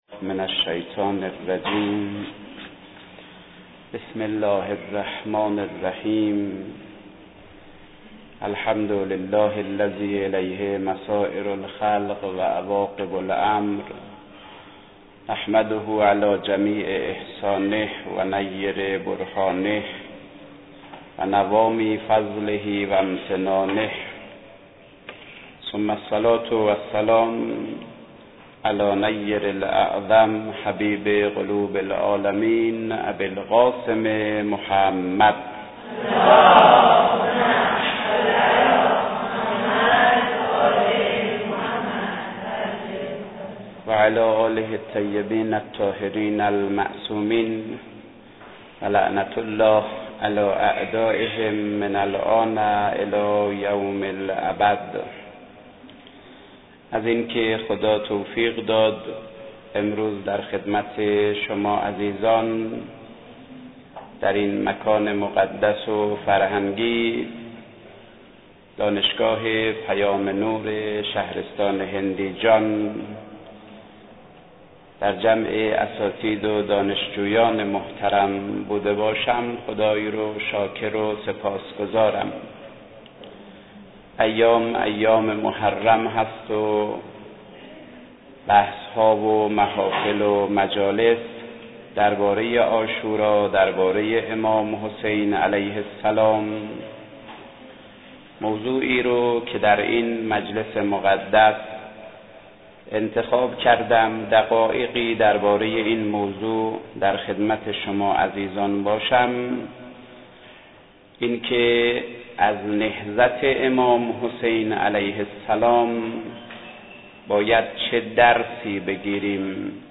سخنرانی
در دانشگاه پیام نور واحد هندیجان